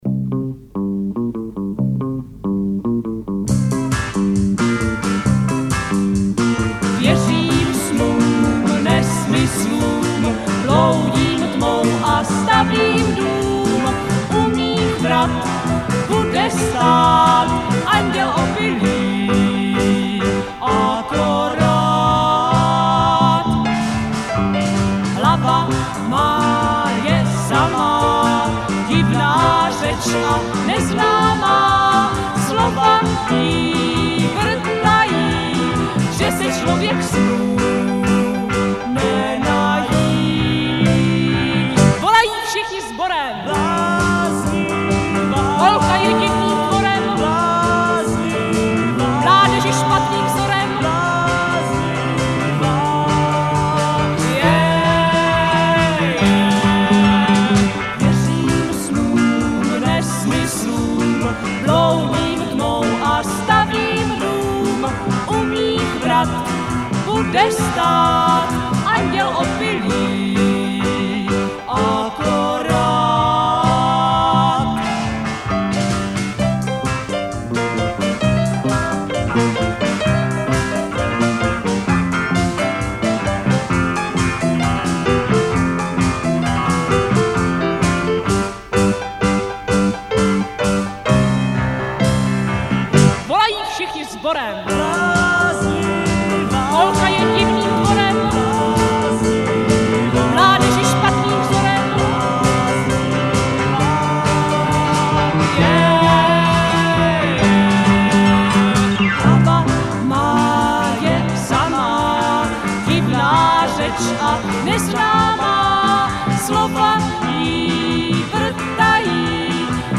Nahráno v rozhlasovém studiu ČR Plzeň 1968. zobrazit celý dlouhý text Rok